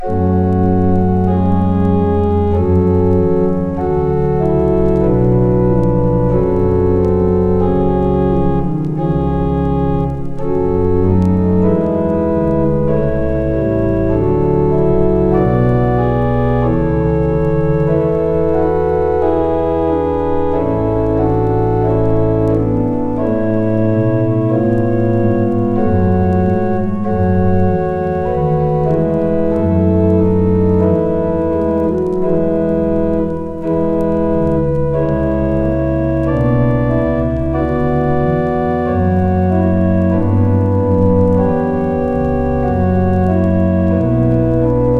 Classical, Baroque　France　12inchレコード　33rpm　Mono